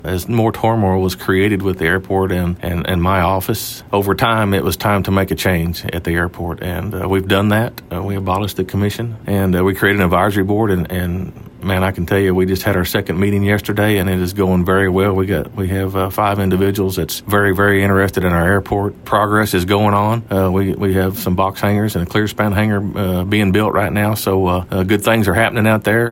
KTLO News caught up with the Judge to discuss the upcoming campaign and his motives for seeking re-election.